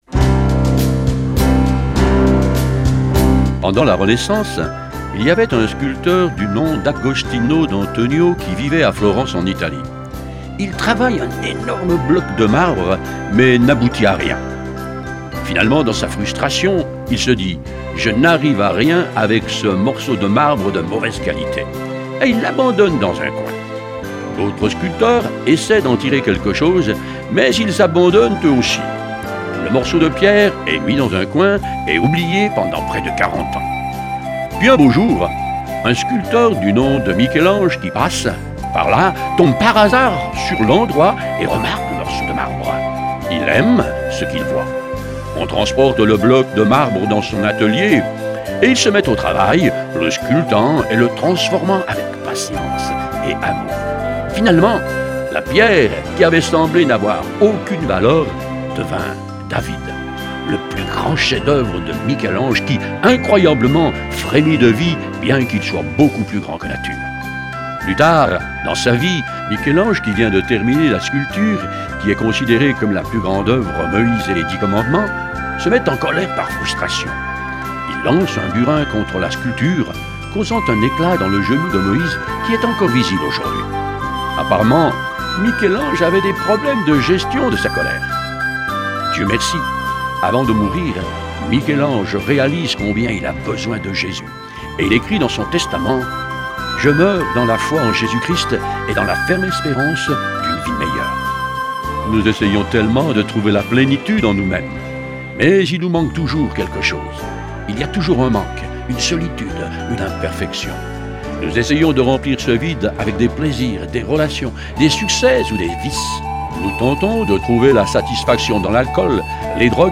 Version audio Phare FM :